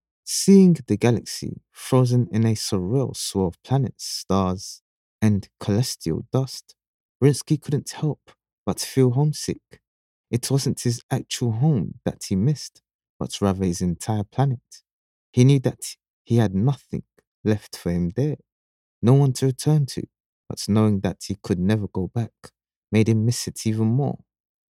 Audio Book Voice Over Narrators
English (Caribbean)
Yng Adult (18-29) | Adult (30-50)